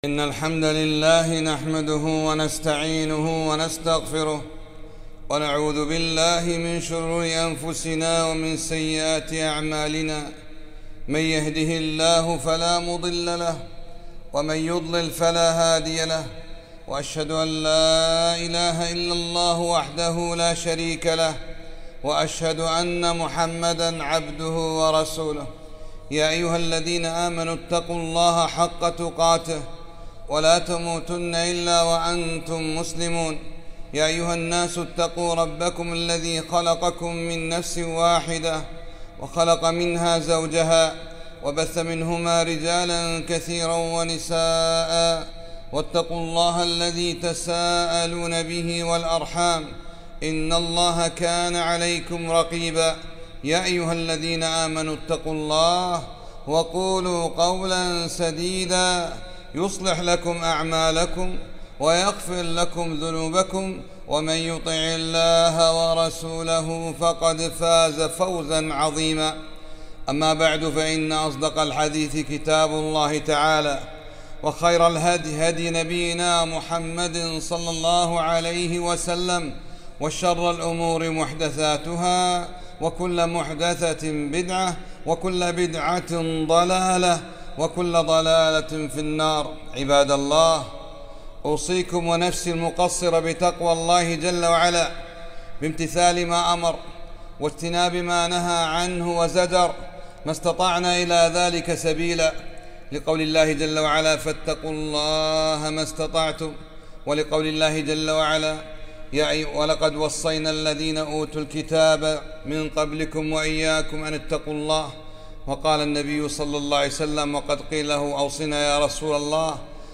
خطبة - دروس من جائحة كرونا ، والعذر من الغفلة